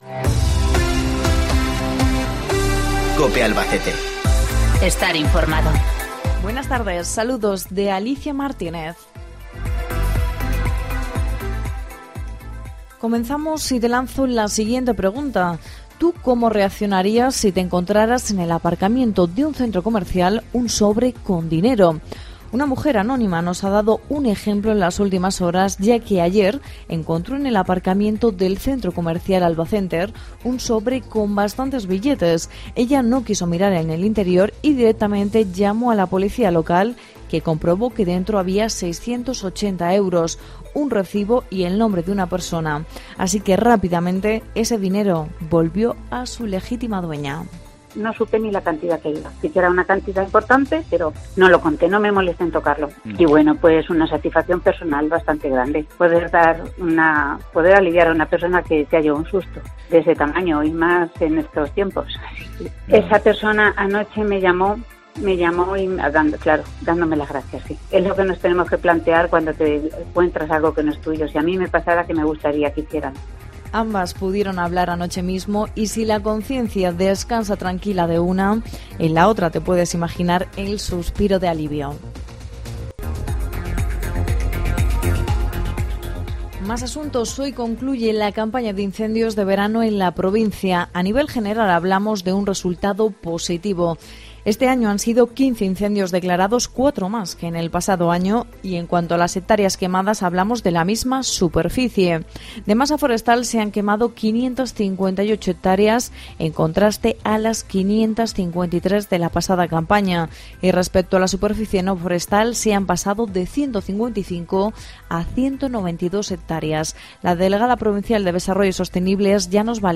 INFORMATIVO LOCAL 30